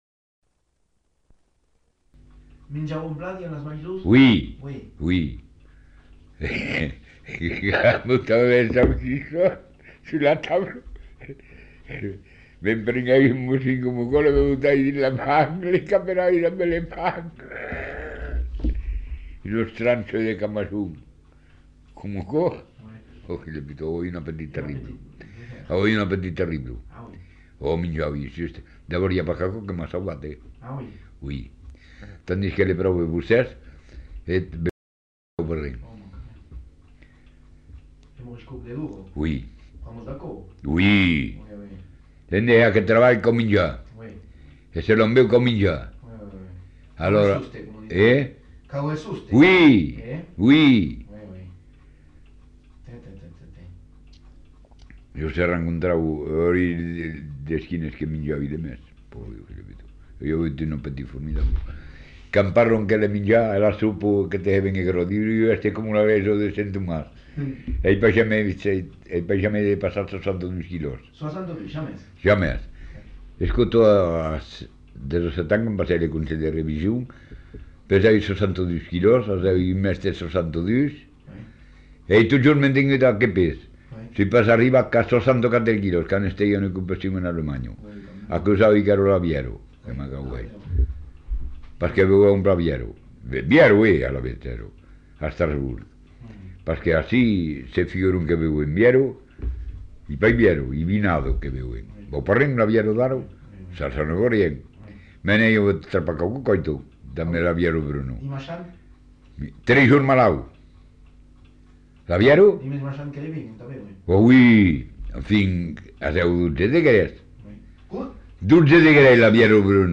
Aire culturelle : Savès
Genre : récit de vie